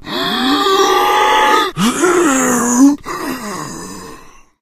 fracture_attack_2.ogg